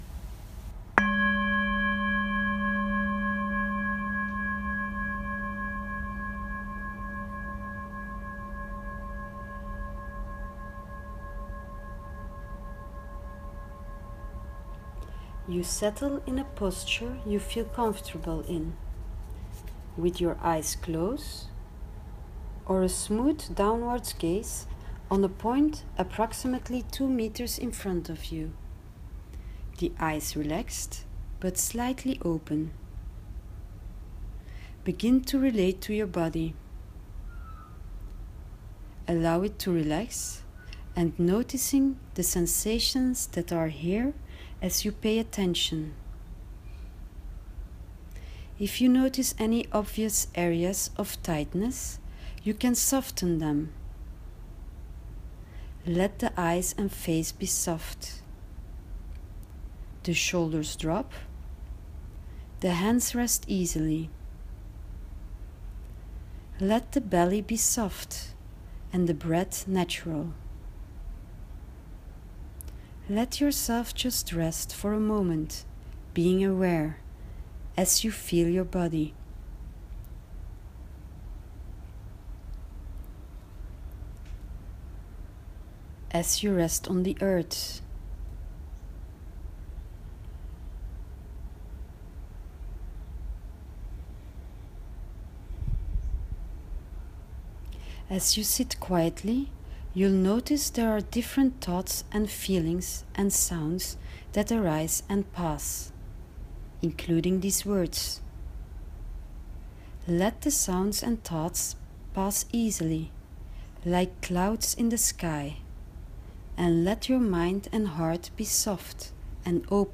You’ll find the guided meditation as a voice recording under the screen with the introduction video.